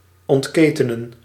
Ääntäminen
Ääntäminen Haettu sana löytyi näillä lähdekielillä: venäjä Käännös Ääninäyte 1. ontketenen 2. maken 3. produceren Translitterointi: vypuskat.